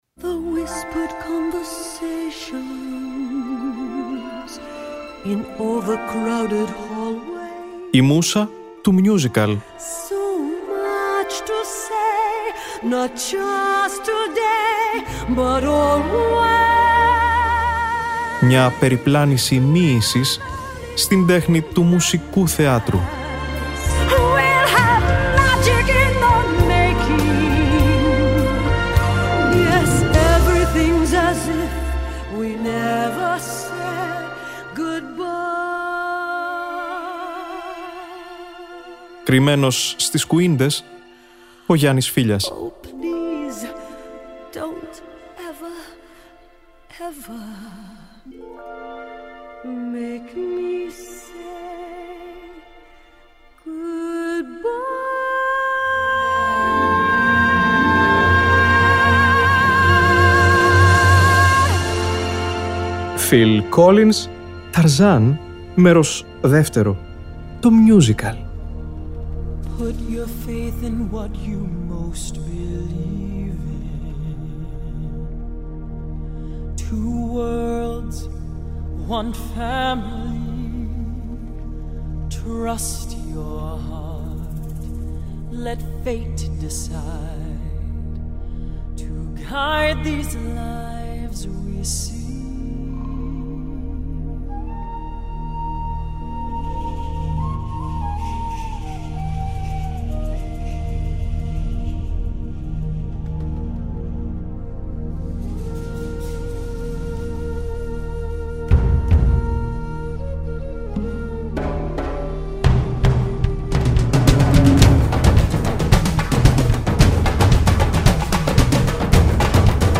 Η ηχογράφηση του original Broadway cast έφτασε στο Νο 3 του Billboard “Cast Album” chart και εμείς θα την απολαύσουμε αυτή την Τετάρτη στις 19.00 , στη “Μούσα του Musical” .